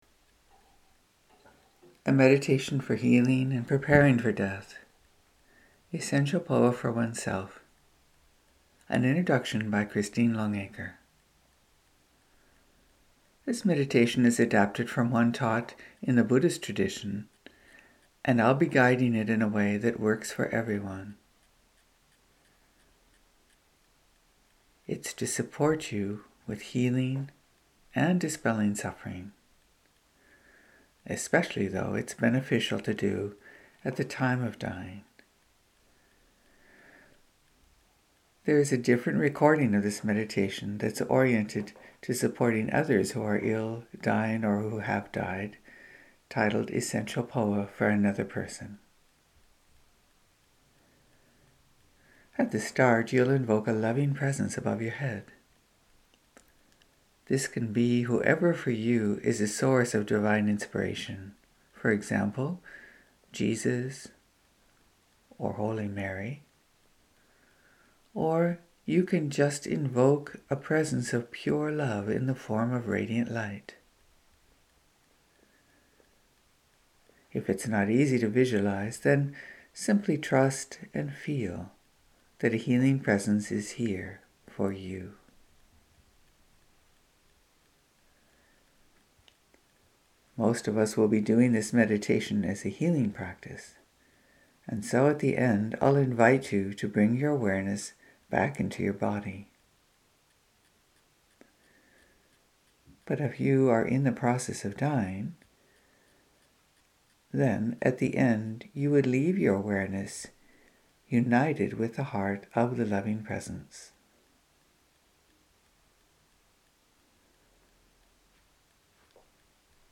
Guided Meditations for you